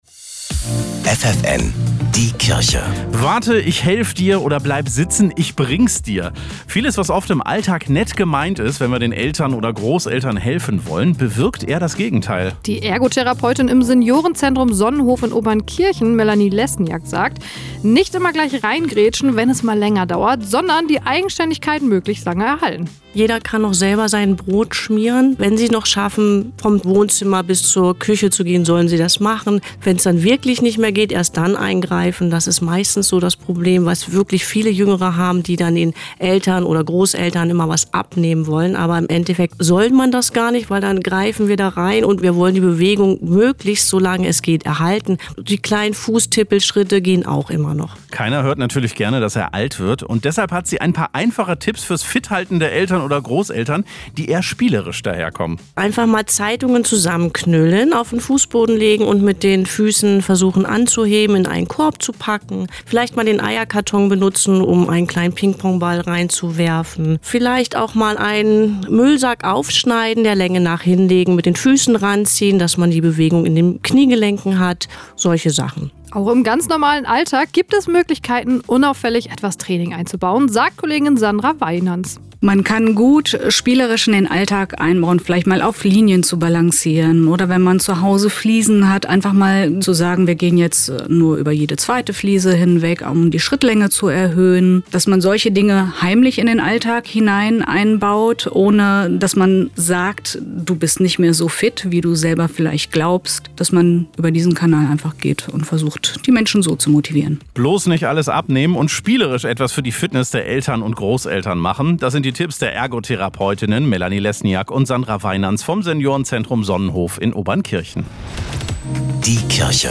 Mitschnitt-ffn-die-Kirche-Seniorenzentrum-Obernkirchen.mp3